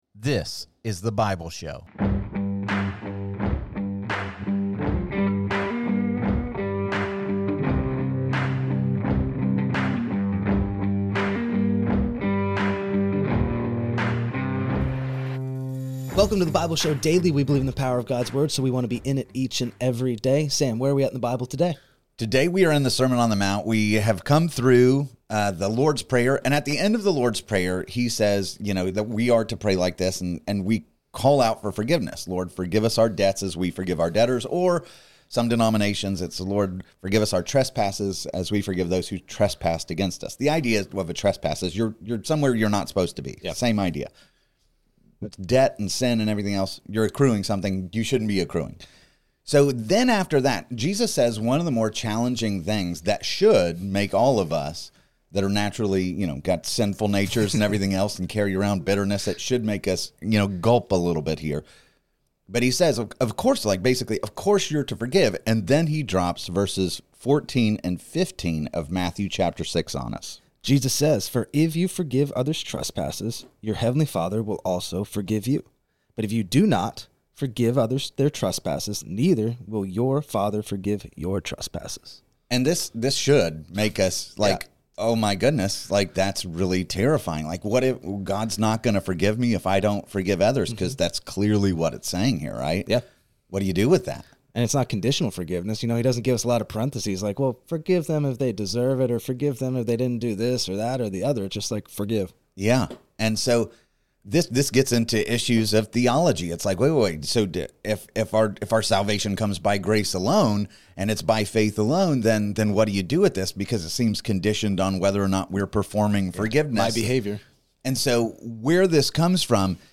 This podcast offers captivating discussions about various passages of the Bible -- examining their historical context, their relevance for modern life, and how these ancient stories point our hearts and minds to Jesus.